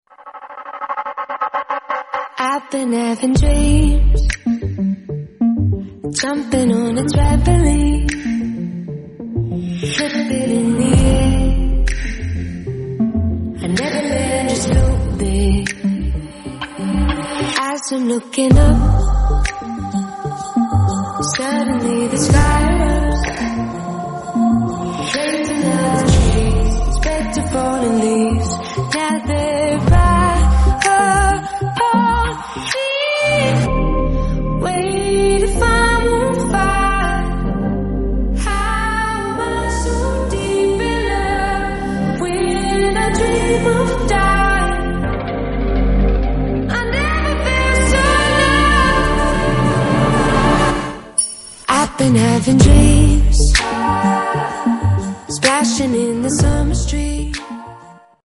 8D sound + 3D art sound effects free download